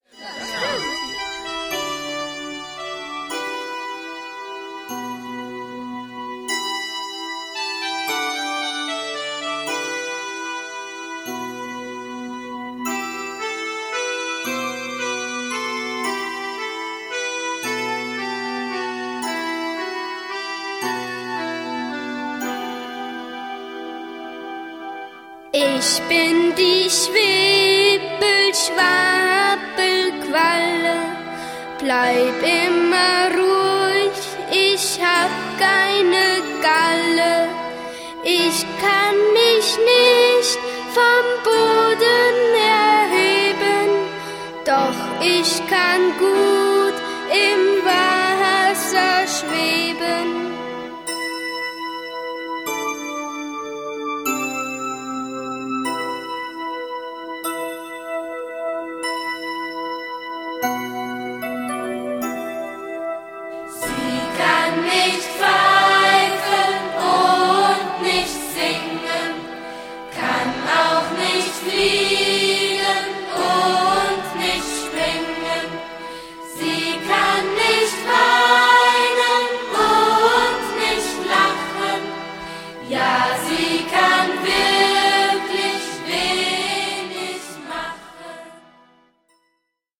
Ein Musical für Kinder